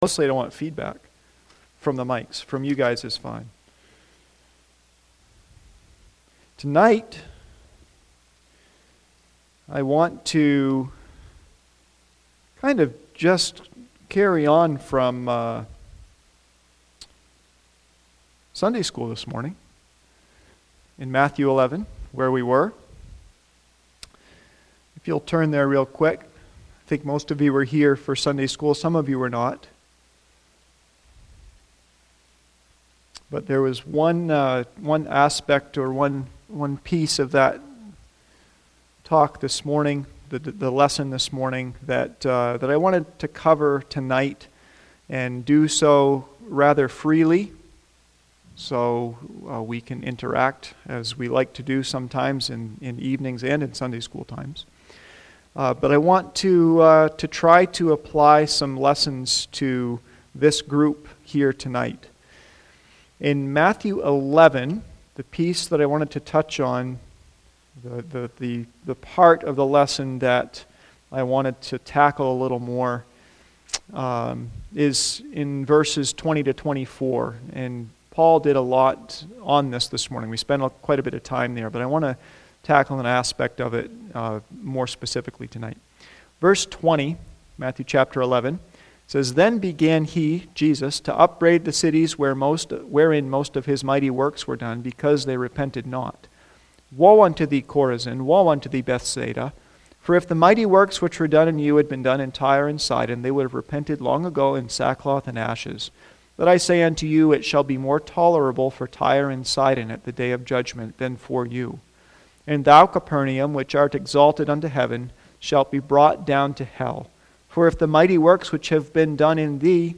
Matthew 11:21-24 Service Type: Sunday PM « May 18